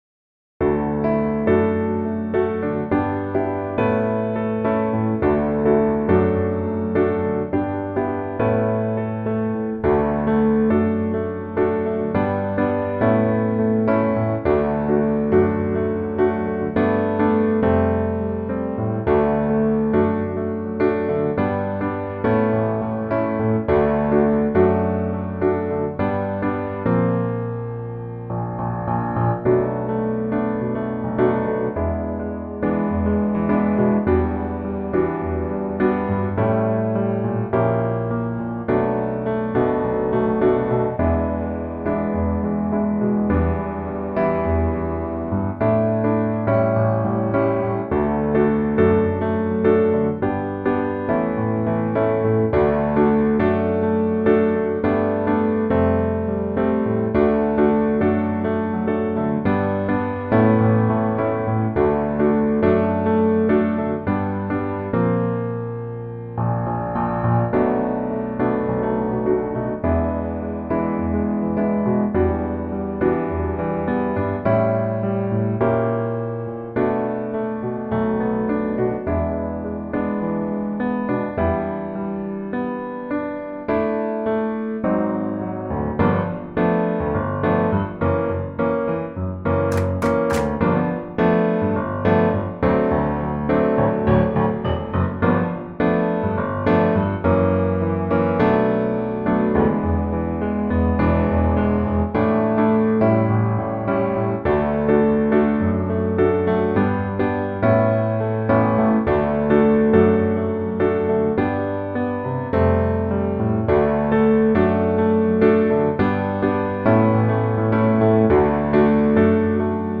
syng-min-ven-klaver-akkompagnement.mp3